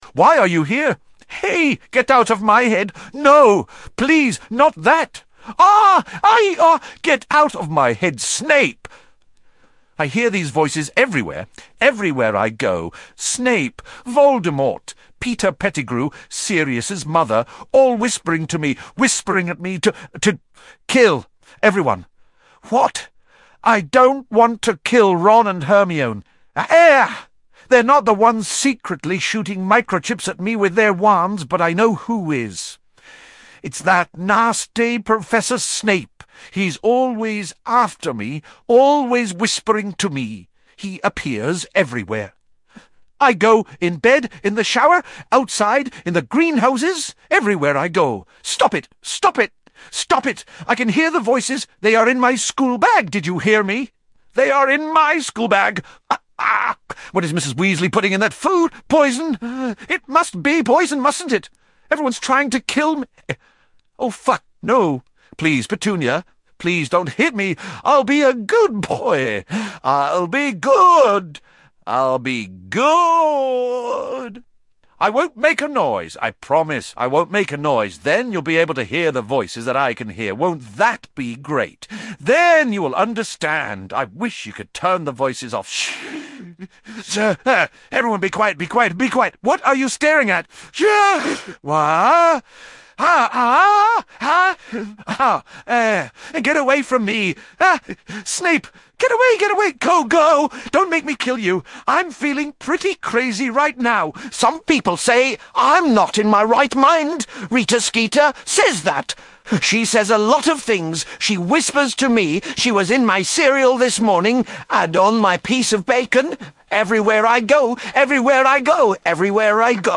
I put the variability and the enhancement sliders to 0 and this is what I got.